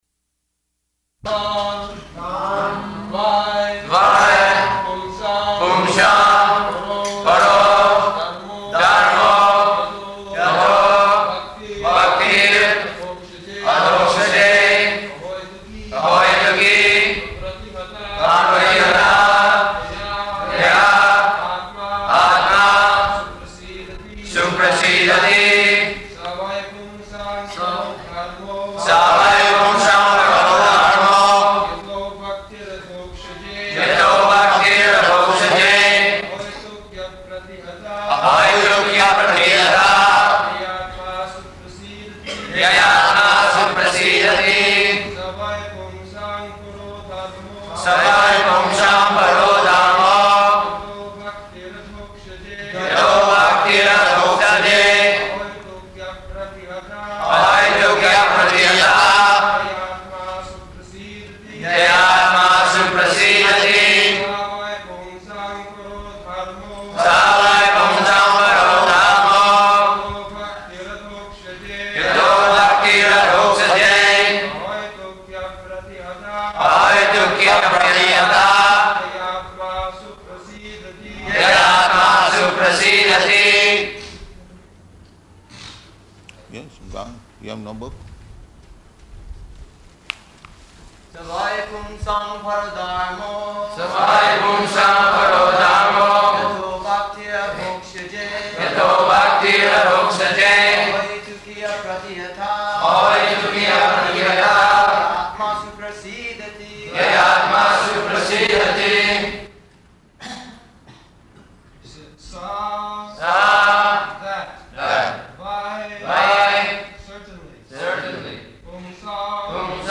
February 27th 1974 Location: Calcutta Audio file
[devotees repeat] sa vai puṁsāṁ paro dharmo yato bhaktir adhokṣaje ahaituky apratihatā yayātmā suprasīdati [ SB 1.2.6 ] Prabhupāda: Yes, go on.